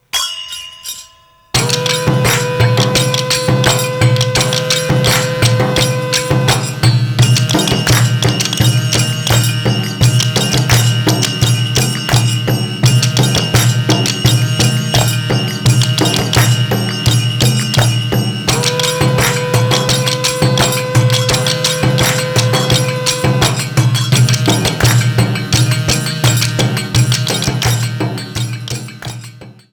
ENSEMBLE
04_Ensemble.aif